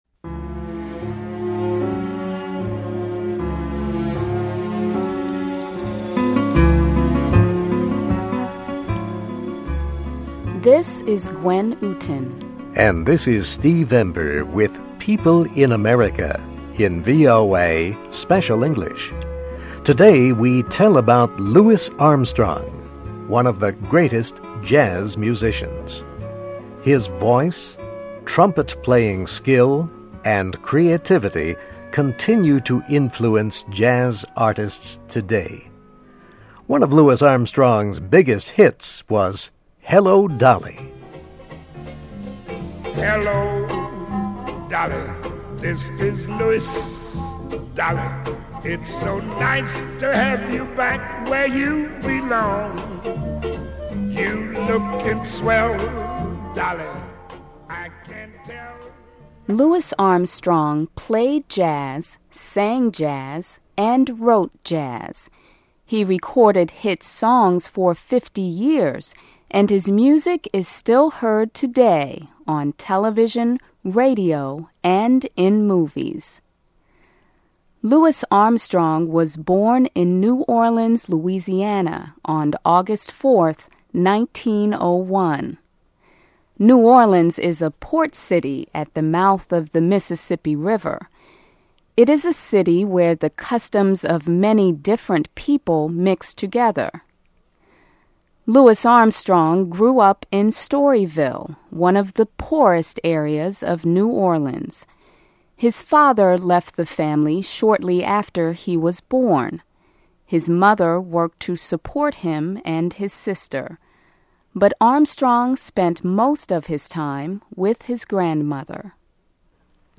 Listen and Read Along - Text with Audio - For ESL Students - For Learning English
voa-se-pia-louis-armstrong-28sept08.mp3